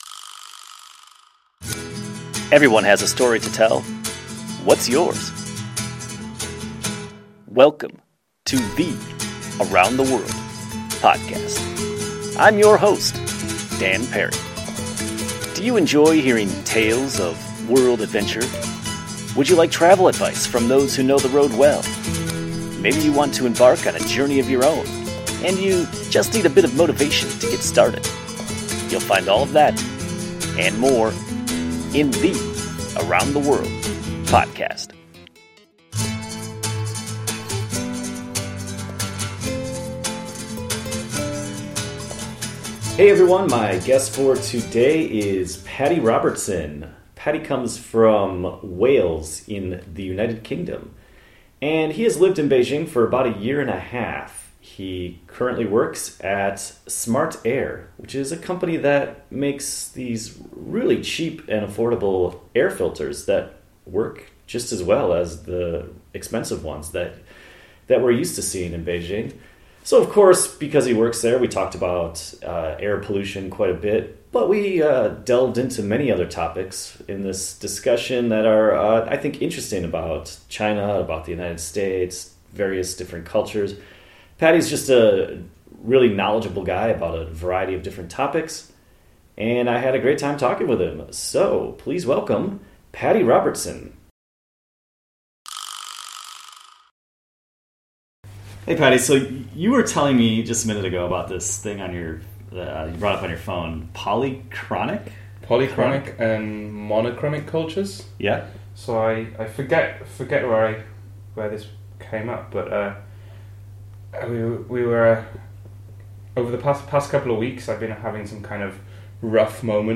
Did you enjoy our discussion?